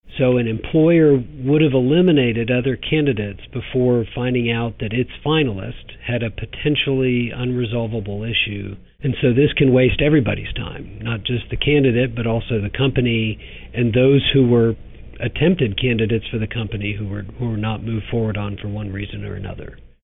In Austin’s case, banning the box is just one facet of the fair-chance hiring ordinance Council members are considering. Listen to Council Member Greg Casar explain this nuance: